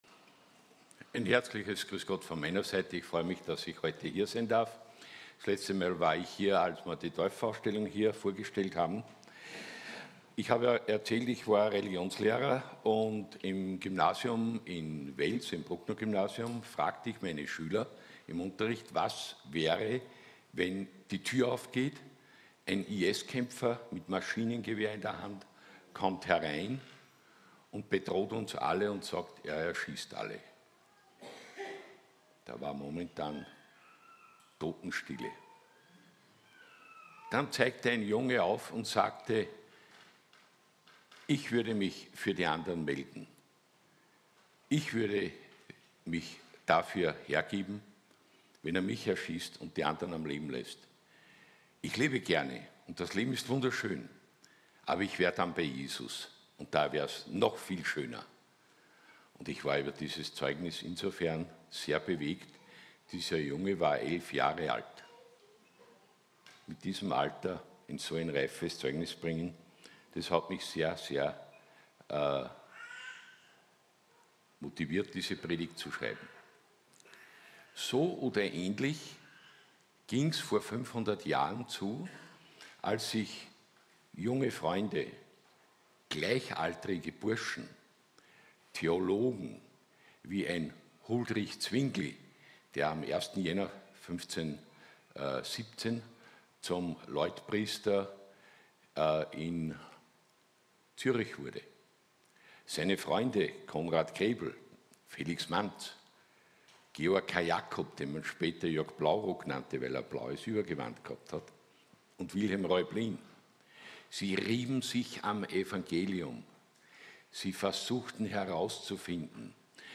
Weitere Predigten